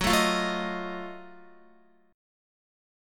Listen to GbmM13 strummed